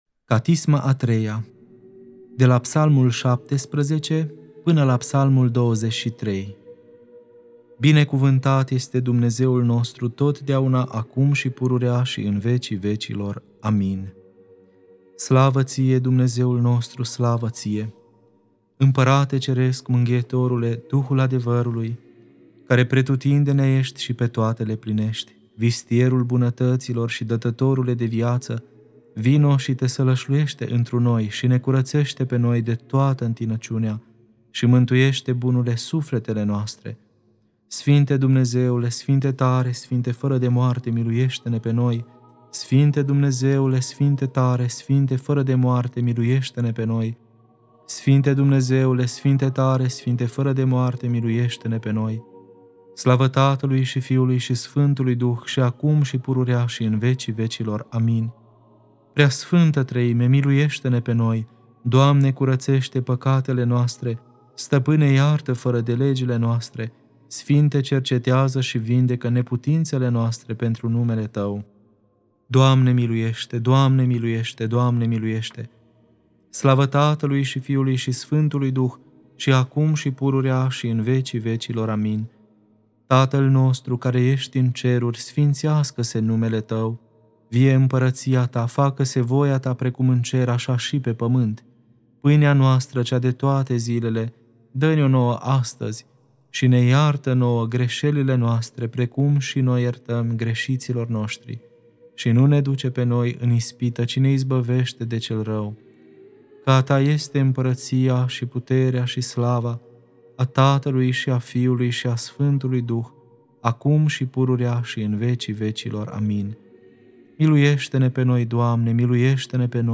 Catisma a III-a (Psalmii 17-23) Lectura